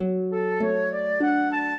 flute-harp
minuet13-8.wav